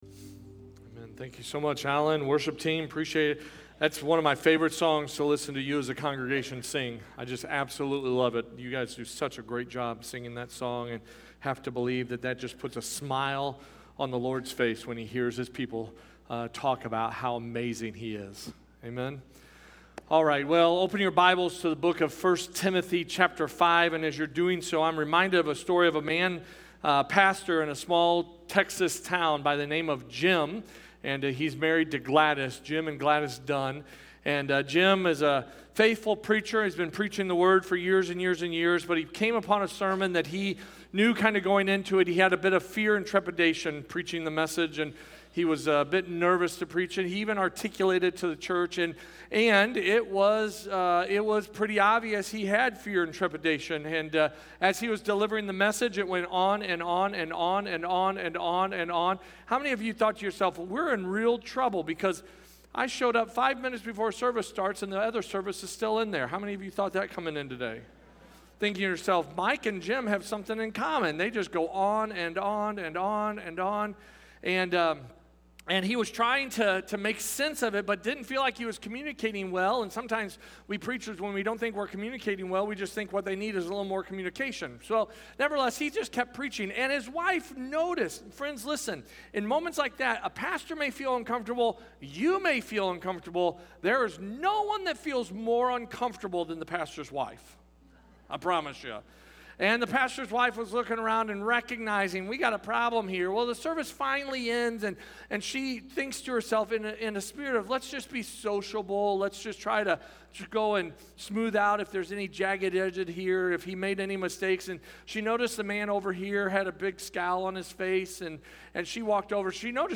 Sermons - First Baptist Church O'Fallon